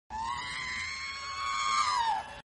Download R2d2 sound effect for free.